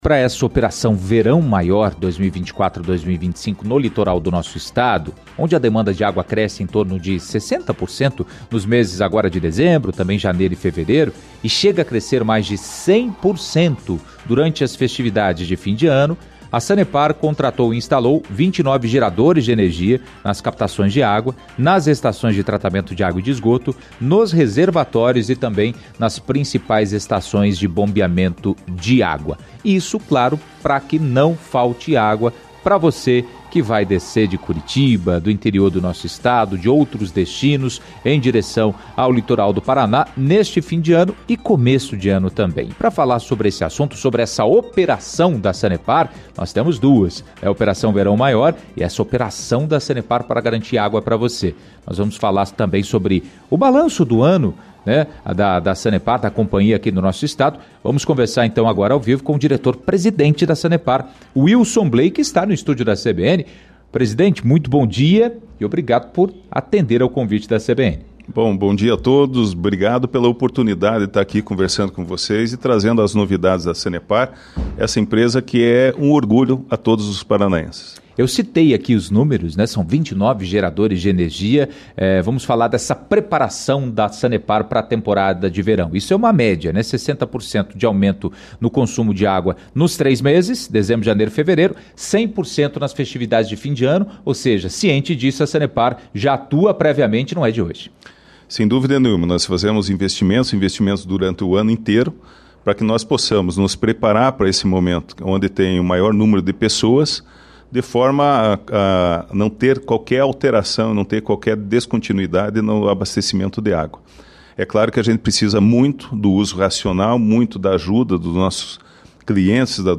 Em entrevista a CBN Curitiba nesta quarta-feira